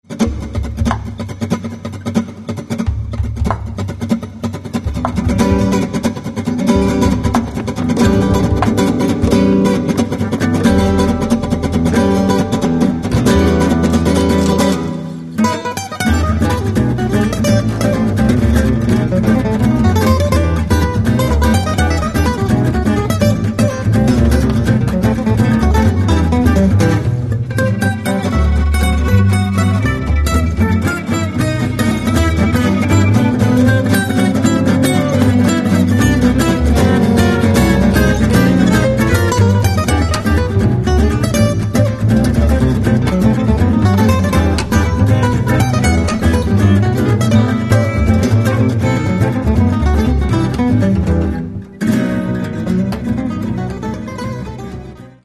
Ну а теперь издан диск с концертной записью
guitar
violin
bass